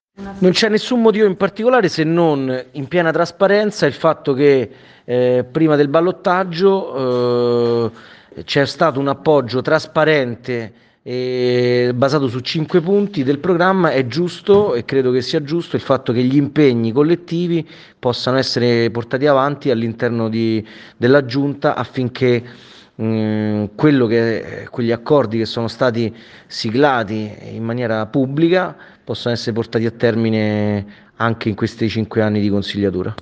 Il presidente Torquati parla con precisione e sintesi, vuole comunicare il senso della sua attività, lo fa con calma e attento che si capisca che è il prodotto di un’azione collettiva.